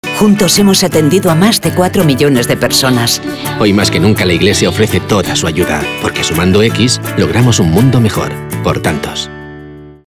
mp3Cuña 10 segundos